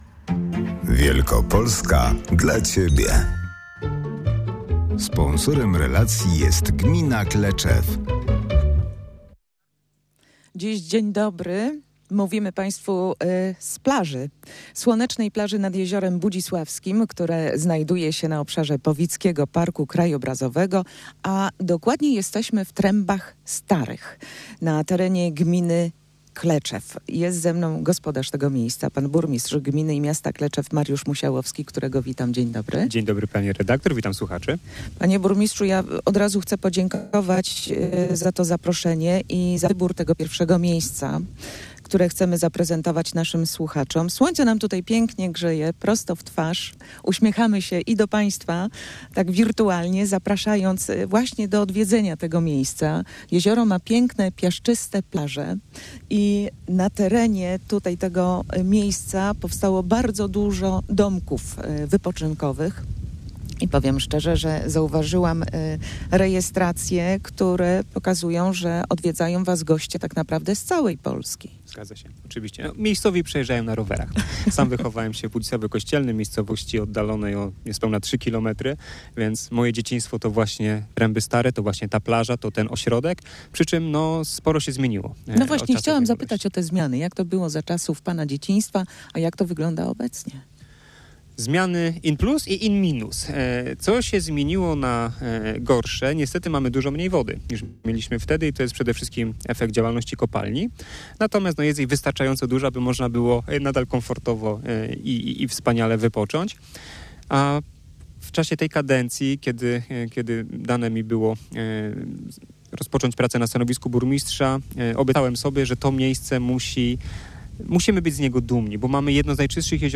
Dziś zawitaliśmy do gminy Kleczew we wschodniej Wielkopolsce. To kolejny przystanek naszego wakacyjnego programu "Wielkopolska dla Ciebie".
Wizytę rozpoczęliśmy od malowniczego Jeziora Budzisławskiego w Trębach Starych, które znajduje się na obszarze Powidzkiego Parku Krajobrazowego.